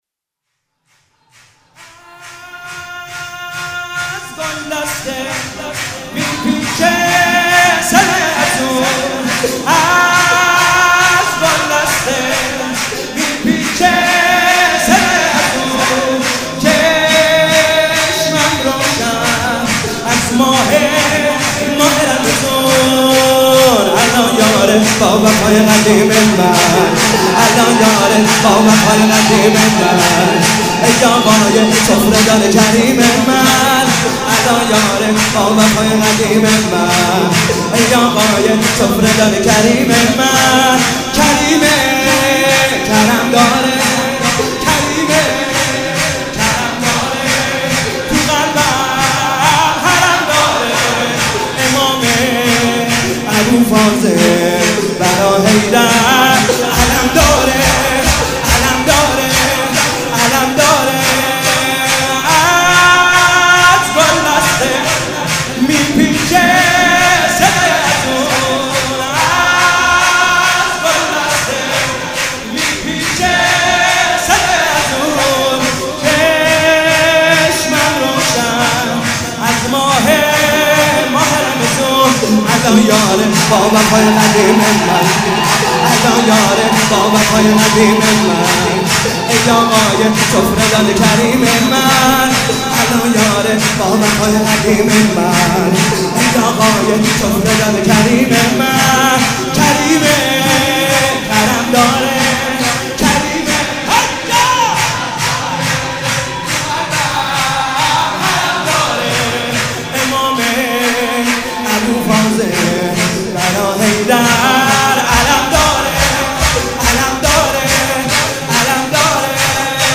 مناسبت : شب شانزدهم رمضان
مداح : کربلایی حسین طاهری قالب : شور